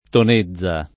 [ ton %zz a ]